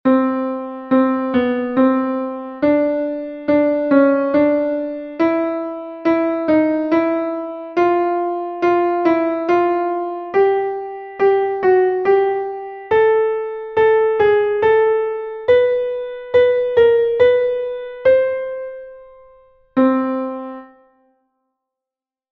Exercise 2: 3/4 time signature.